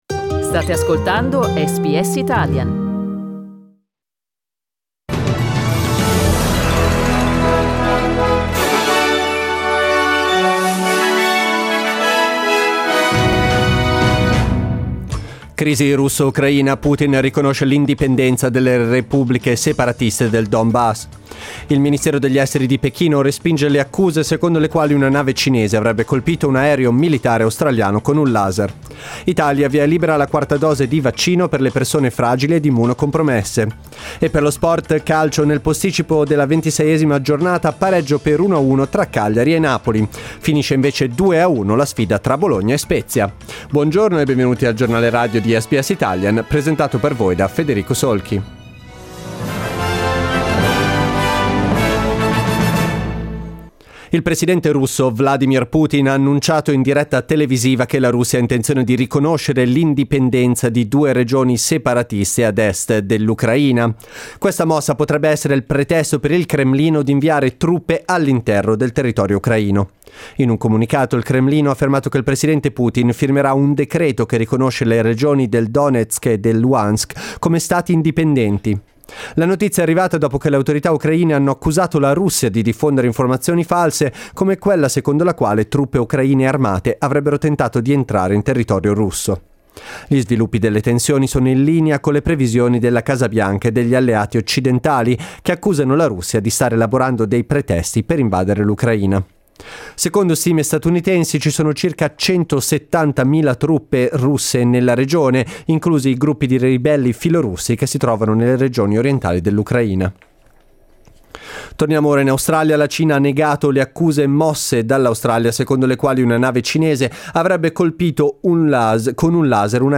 Giornale radio martedì 22 febbraio 2022
Ascolta l'edizione di oggi del notiziario di SBS Italian: